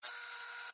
Звук удаляющейся камеры (Обратный зум)